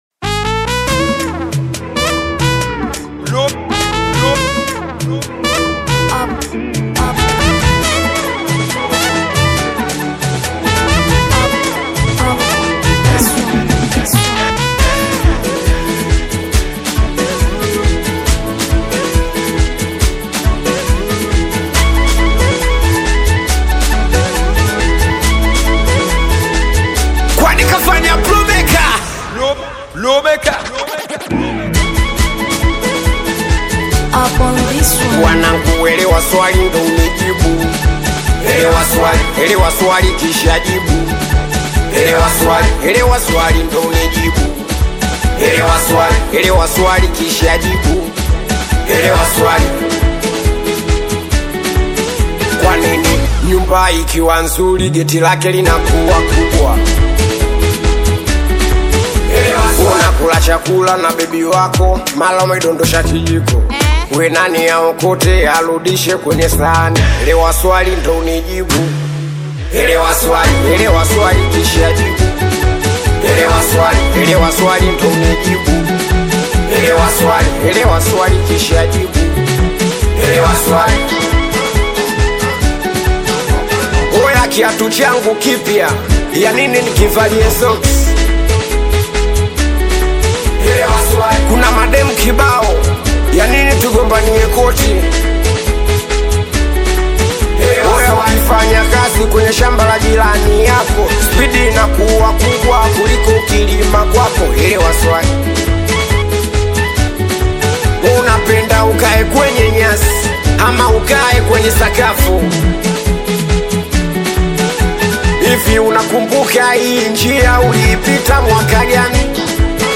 Bongo Flava singeli
Singeli